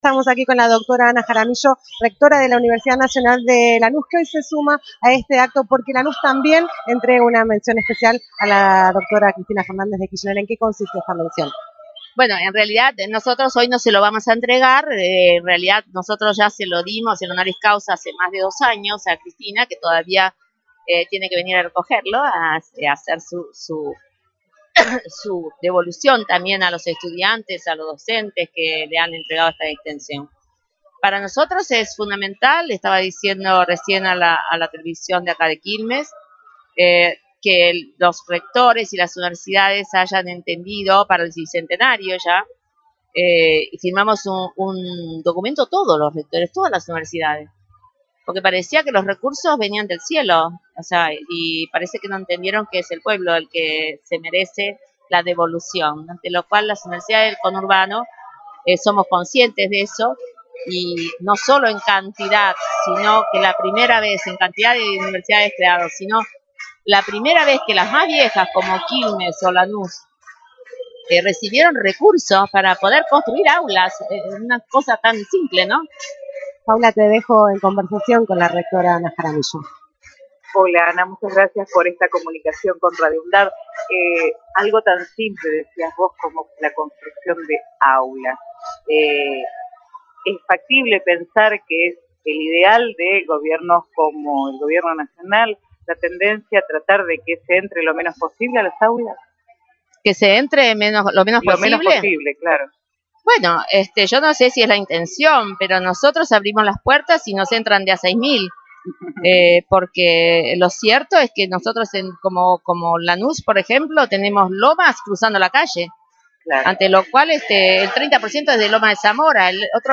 Honoris Causa CFK 03 - ENTREVISTAS | Radio UNDAV